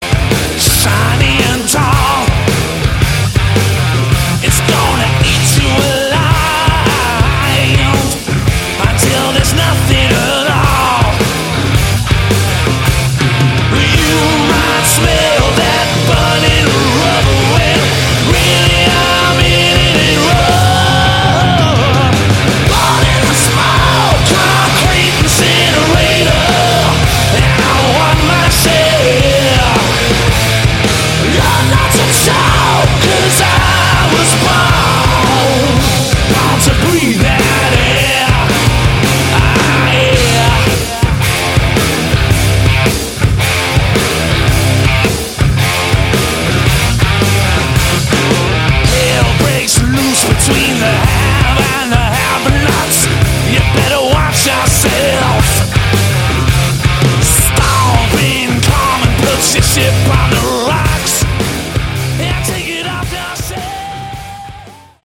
Category: Hard Rock
vocals, bass
guitars
drums